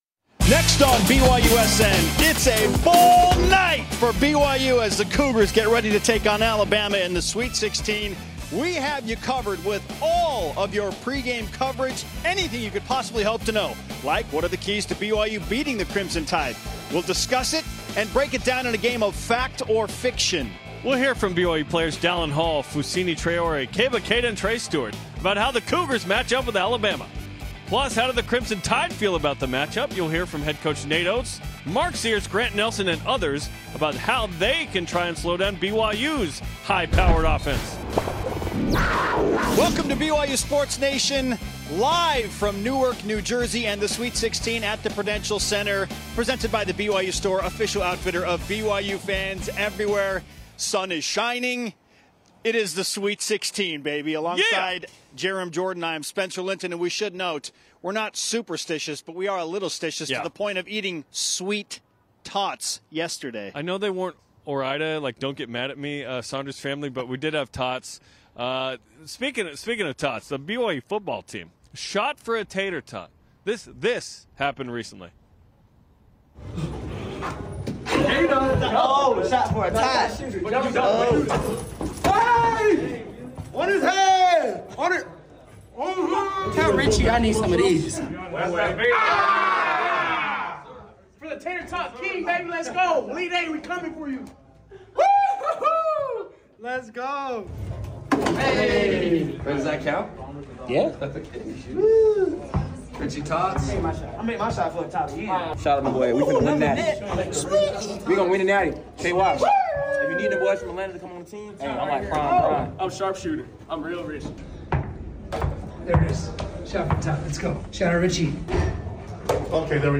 engaging discussion, interviews